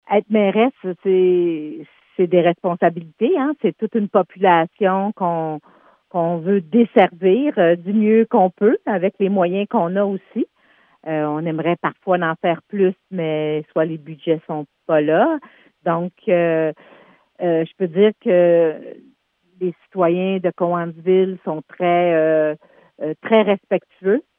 Dans un entretien téléphonique, Mme Beauregard, souligne qu’il y a plusieurs projets qui sont actuellement sur la table et qu’elle souhaite voir se réaliser.